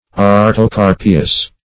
Search Result for " artocarpeous" : The Collaborative International Dictionary of English v.0.48: Artocarpeous \Ar`to*car"pe*ous\, Artocarpous \Ar`to*car"pous\, a. [Gr.